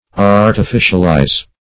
Artificialize \Ar`ti*fi"cial*ize\, v. t. To render artificial.